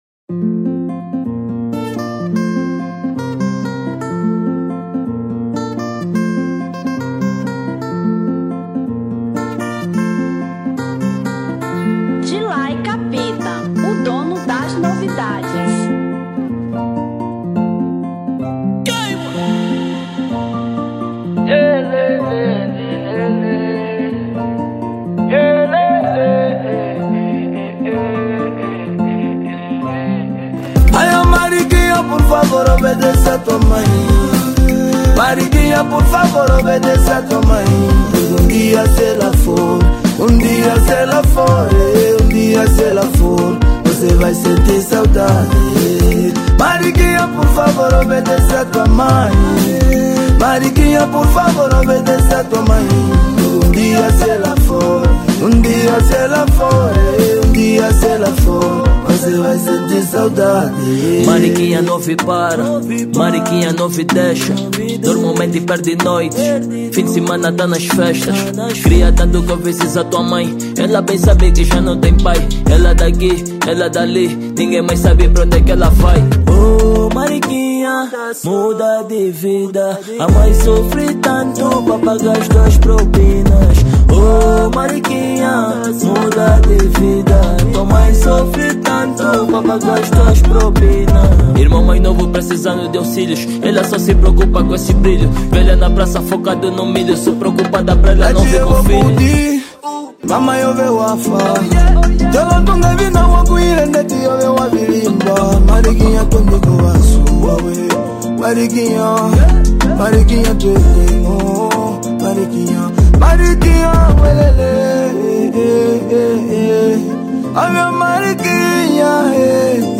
Kilapanga